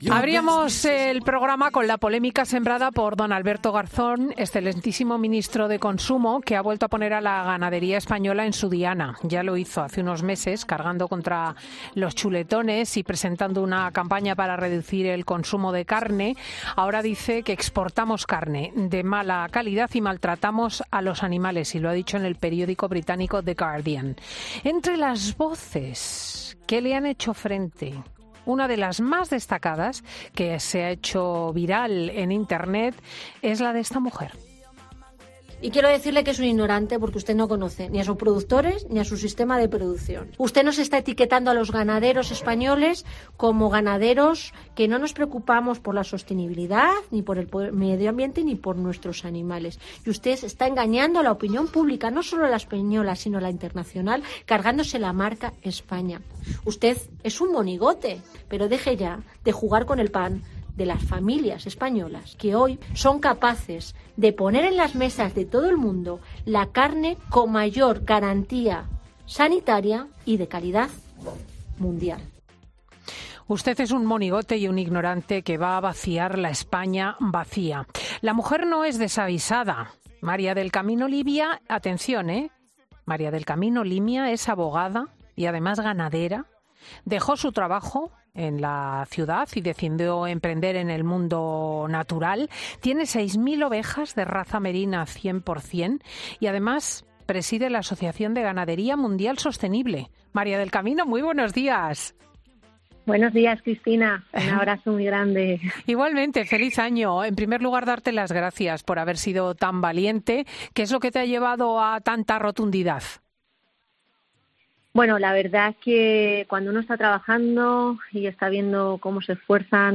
abogada y ganadera, critica en COPE que el ministro no interponga denuncias contra las empresas que asegura que ejercen malas prácticas